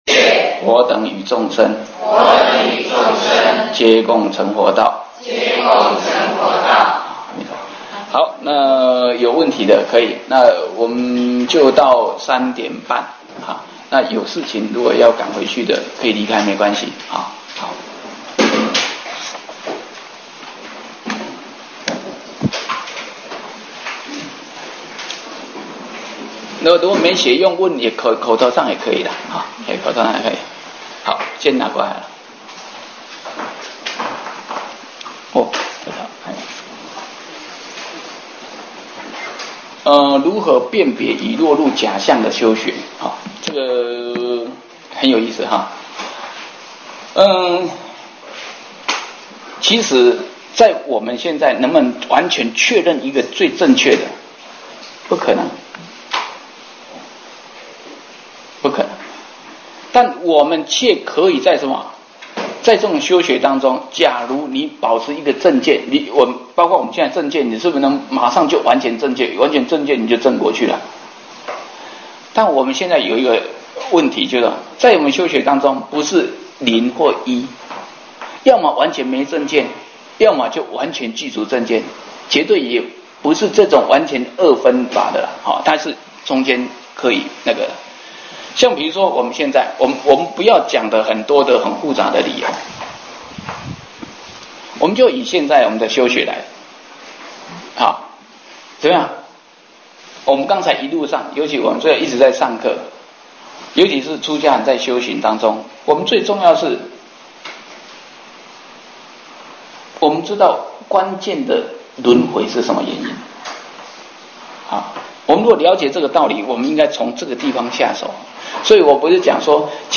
法句经015(问答).mp3